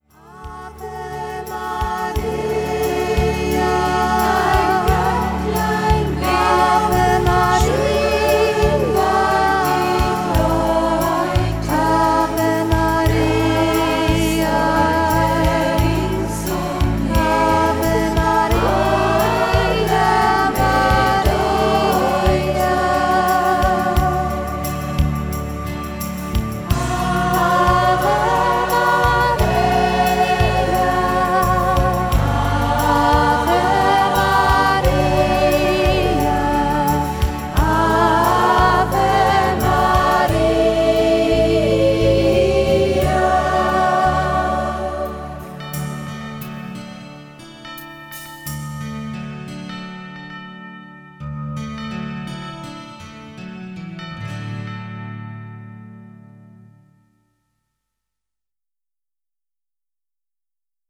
Der Frauenchor mit dem gewissen Extra; Hochzeiten, Festgottestdienste, Geburtstagsständchen, Feiern